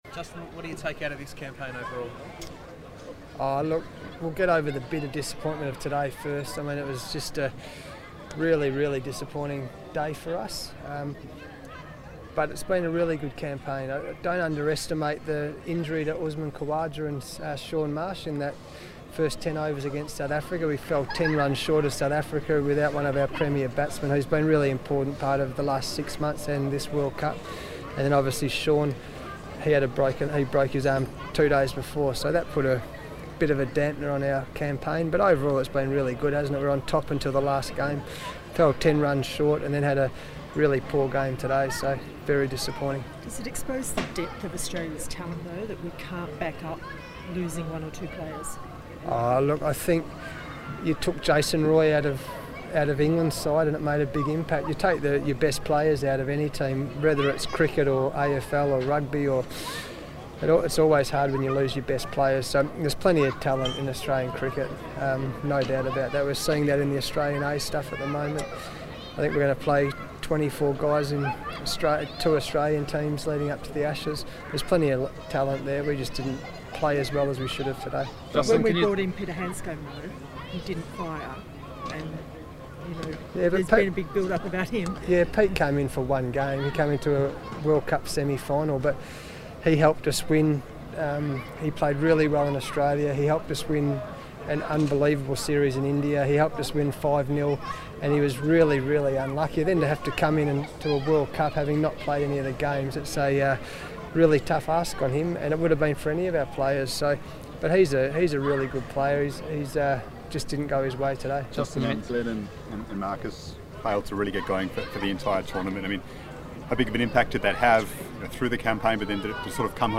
Justin Langer spoke to the media of the team disappointment at being knocked out of CWC19